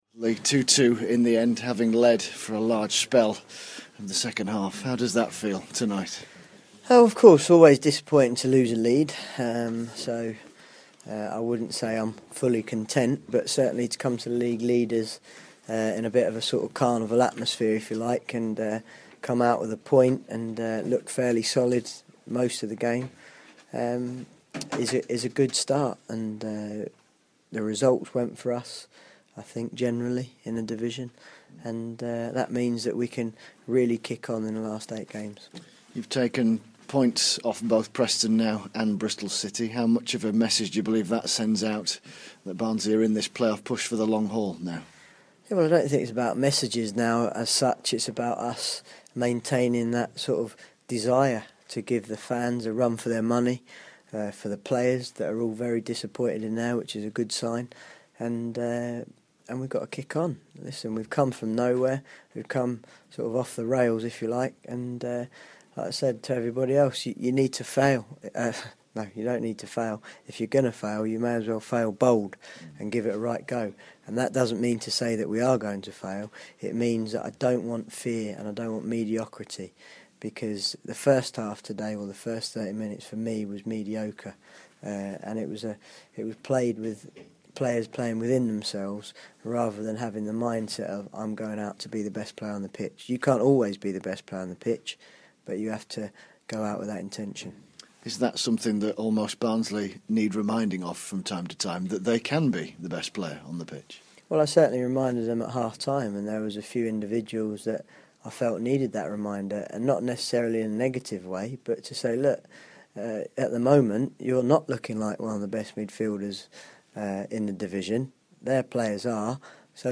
INTERVIEW: Barnsley boss Lee Johnson after the reds 2-2 draw at League Leaders Bristol City